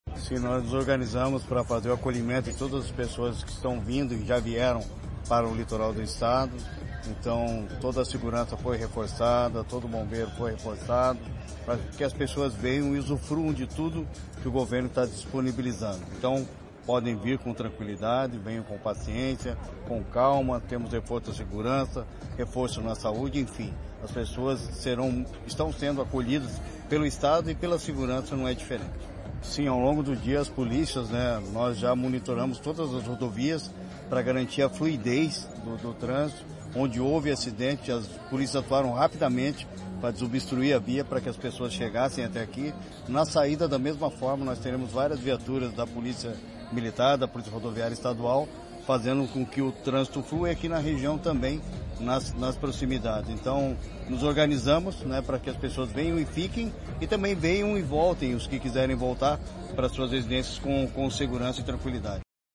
Sonora do secretário da Segurança Pública, Hudson Teixeira, sobre o primeiro fim de semana de shows do Verão Maior Paraná